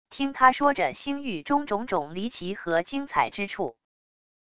听他说着星域中种种离奇和精彩之处wav音频生成系统WAV Audio Player